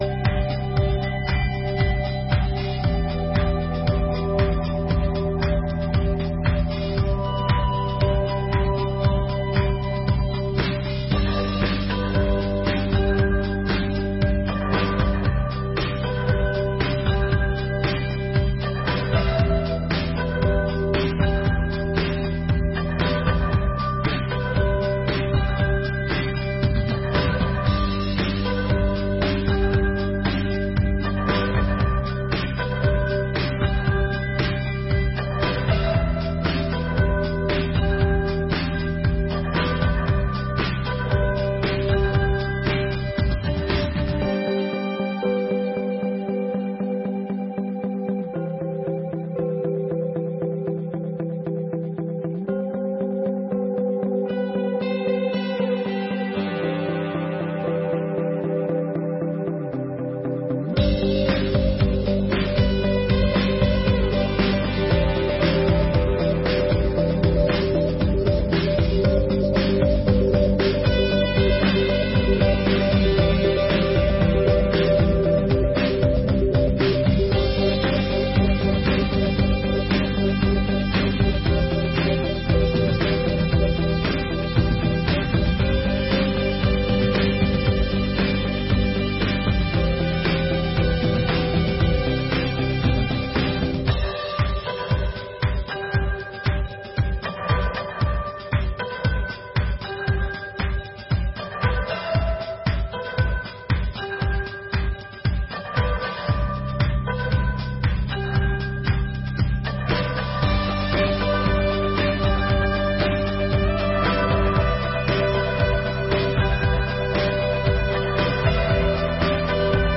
Audiências Públicas de 2024